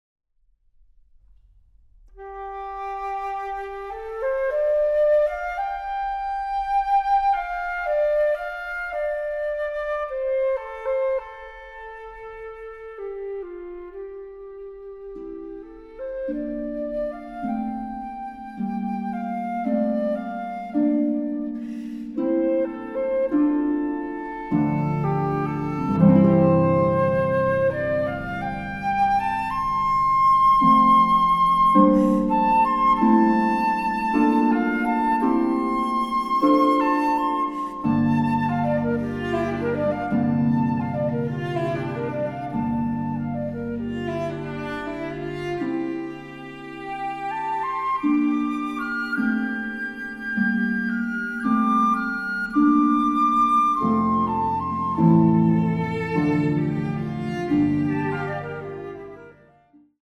arranged for flute, cello, and harp